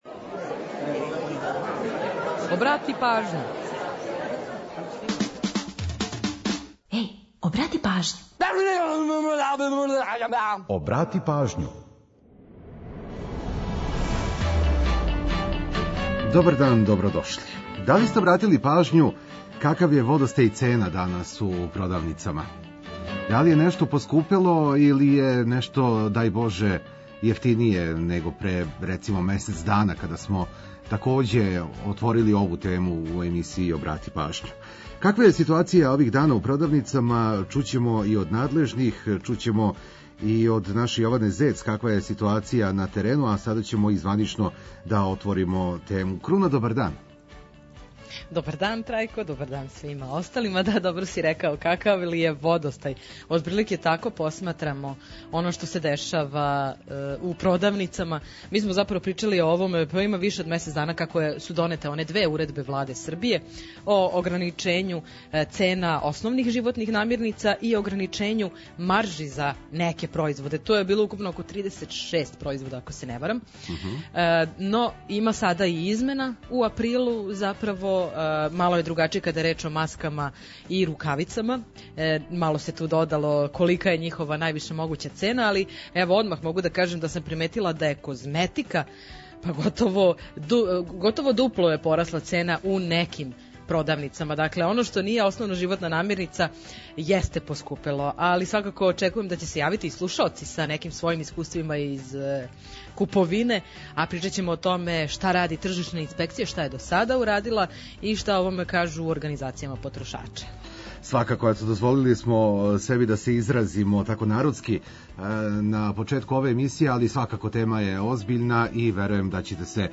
Ту је и пола сата резервисаних само за нумере из Србије и региона.